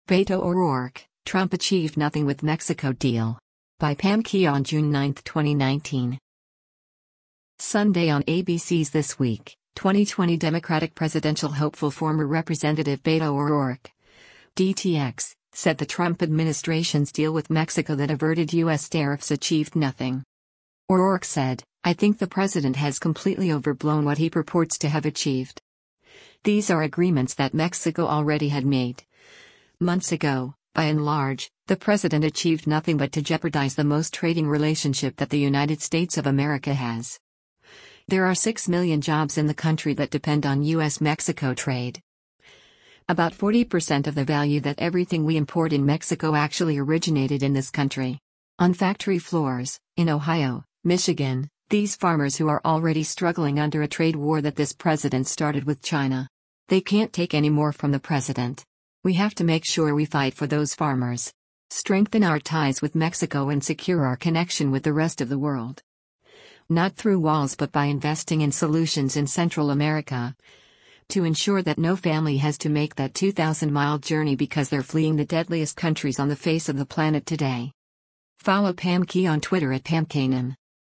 Sunday on ABC’s “This Week,” 2020 Democratic presidential hopeful former Rep. Beto O’Rourke (D-TX) said the Trump administration’s deal with Mexico that averted U.S. tariffs “achieved nothing.”